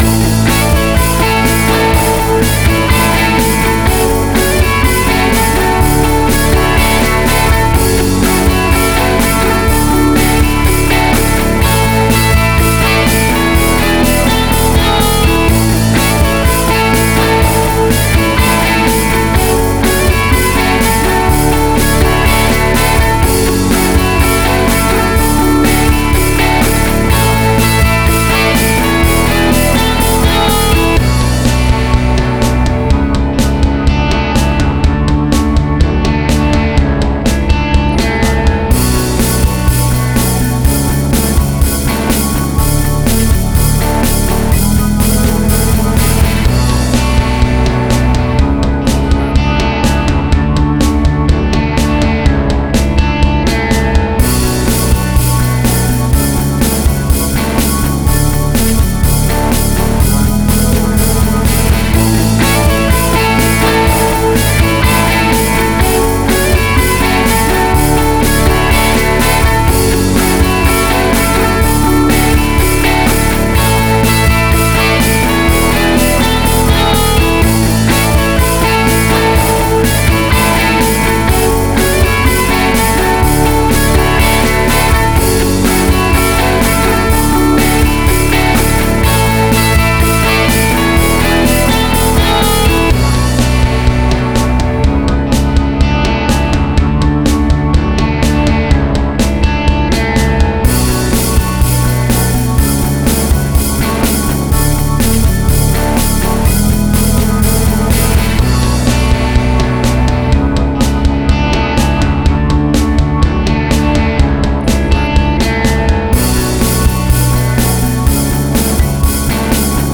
Tempo (BPM): 125